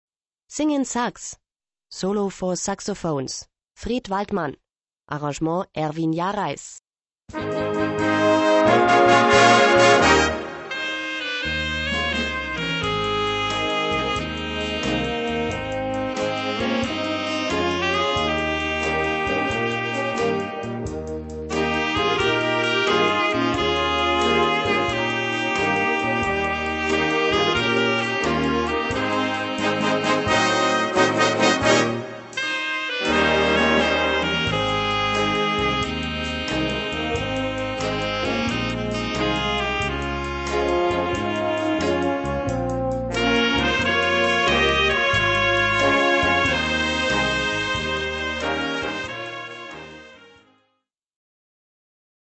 Gattung: Solo für zwei Saxophone und Blasorchester
Besetzung: Blasorchester